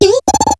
Cri de Charpenti dans Pokémon HOME.